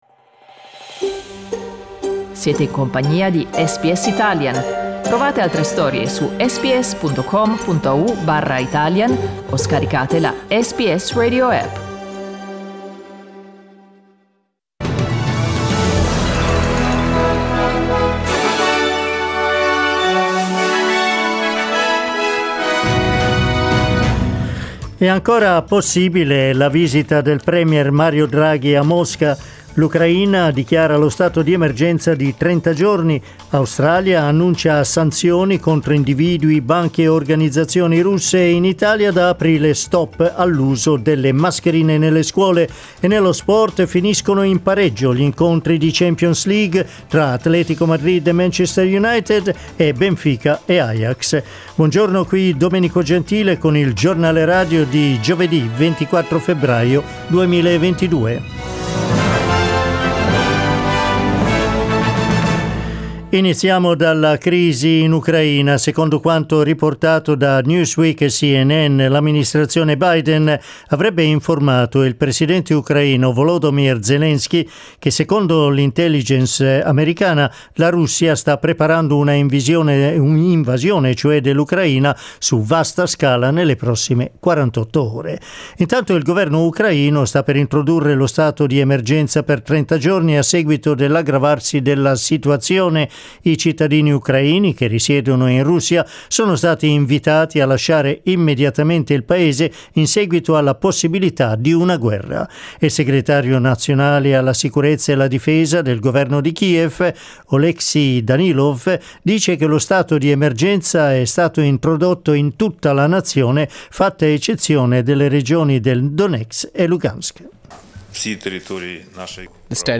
Giornale radio giovedì 24 febbraio 2022
Il notiziario di SBS in italiano.